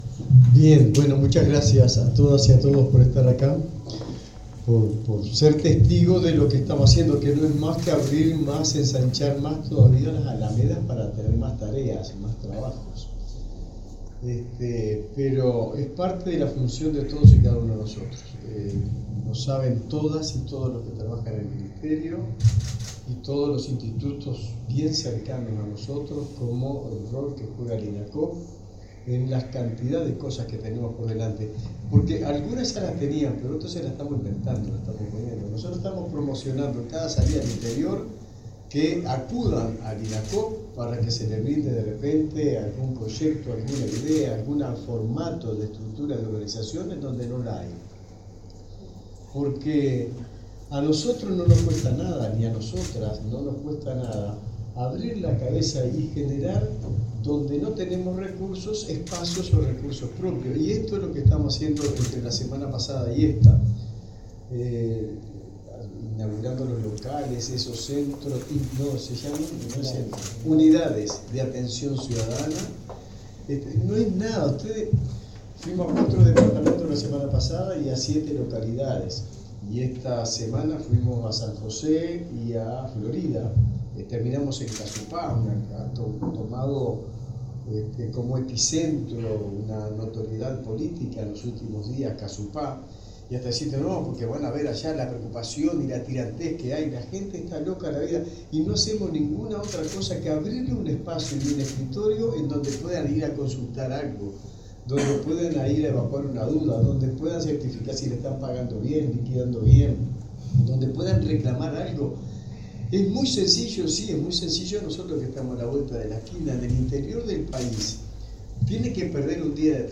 Palabras del ministro de Trabajo y Seguridad Social, Juan Castillo